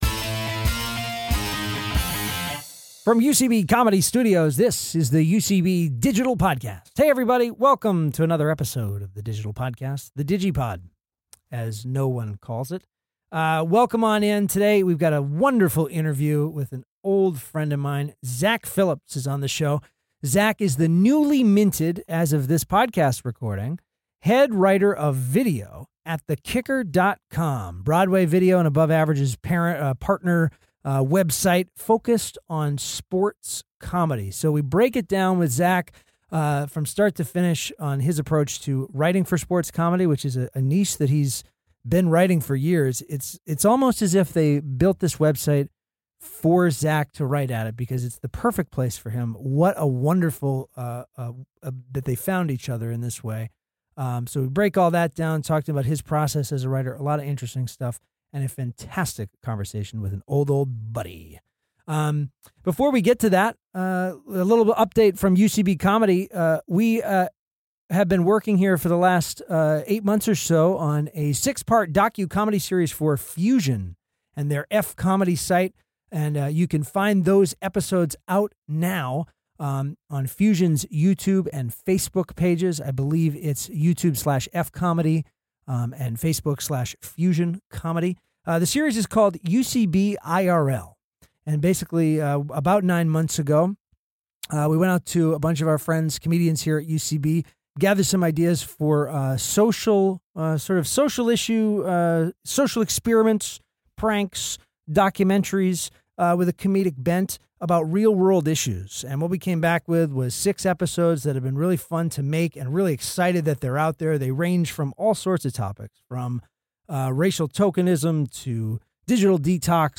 Recorded at UCB Comedy Studios East in New York City.